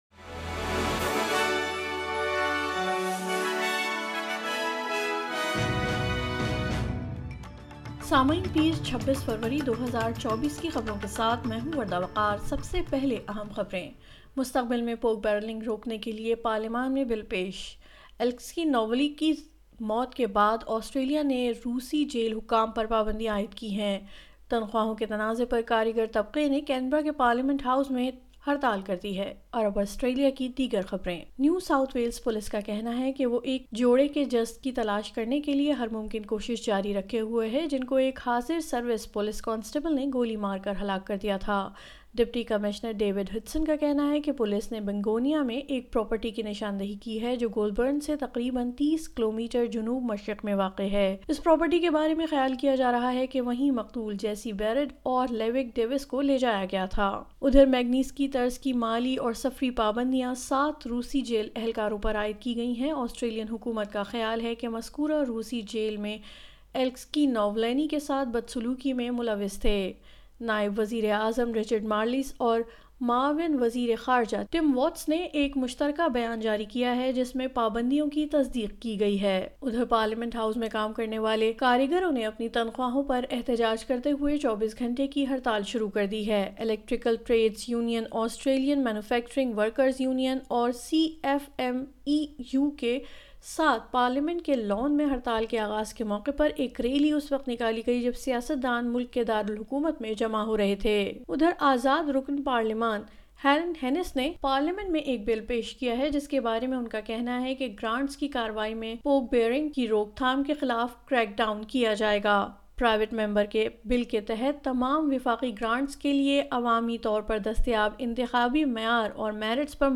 نیوز فلیش:26 فروری 2024:پورک بیرنگ روکنے کے لئے پارلیمان میں بل پیش